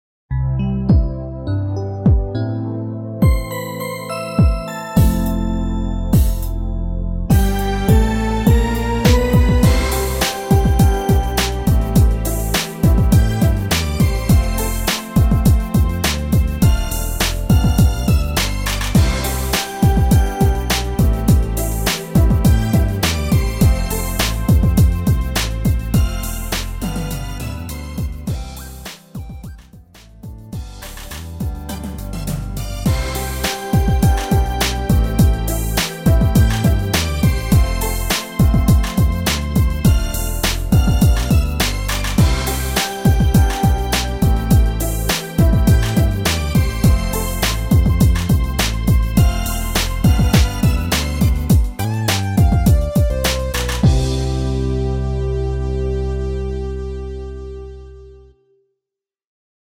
미리듣기는 시작 부분관 엔딩부분으로 되어 있습니다.
앞부분30초, 뒷부분30초씩 편집해서 올려 드리고 있습니다.